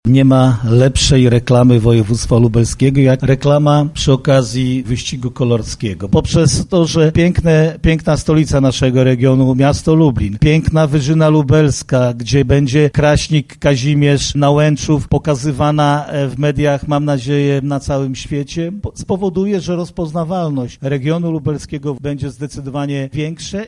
– mówi marszałek województwa lubelskiego Jarosław Stawiarski